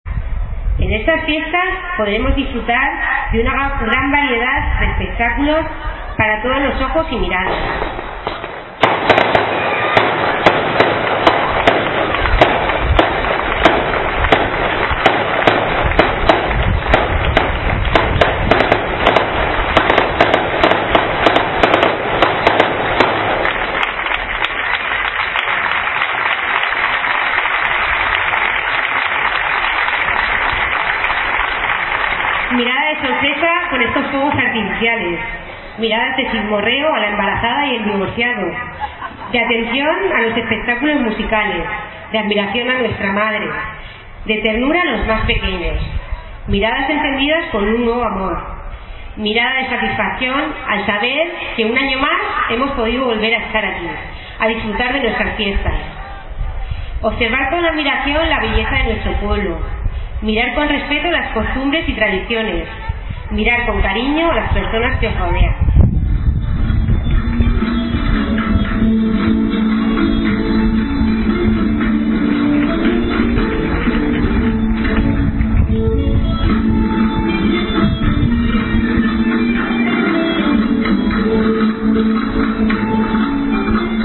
Discurso presidente de la Diputación